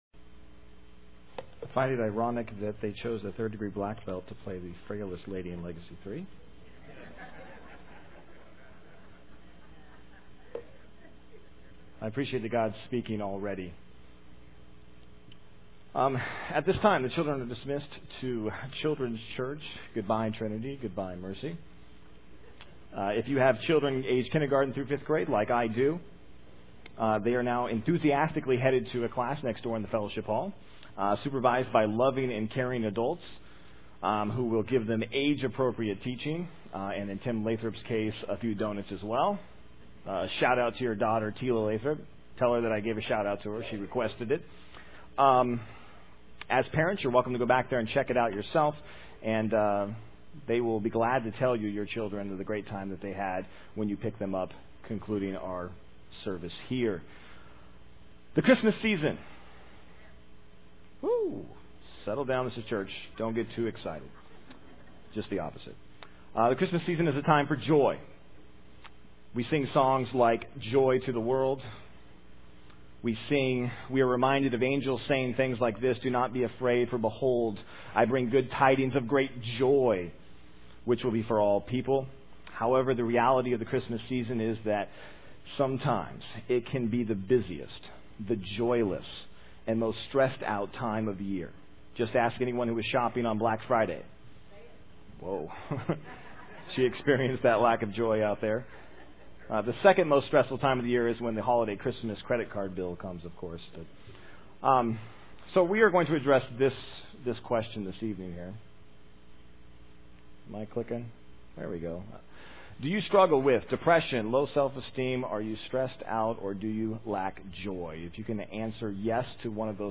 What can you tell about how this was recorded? Main Service am